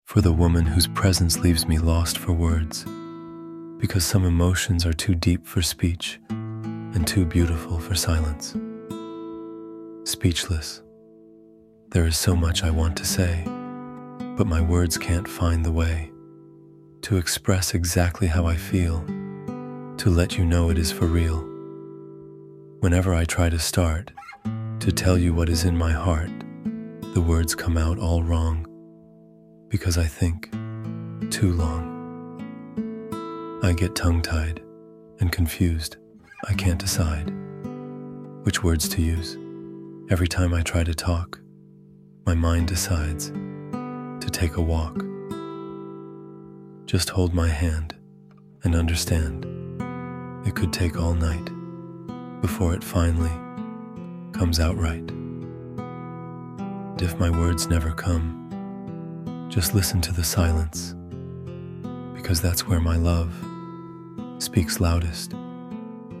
Speechless-–-Love-Poem-Spoken-Word.mp3